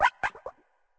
Cri de Nigirigon dans sa forme Courbée dans Pokémon Écarlate et Violet.
Cri_0978_Courbée_EV.ogg